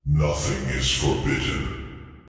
Subject description: Some very magnetic low pitched voices for my self made unit 'Omicron'!
I used professional dubbing tools and workflows, and cooperated with a stunning post-processing assistant.
Very Apocalypse-like these.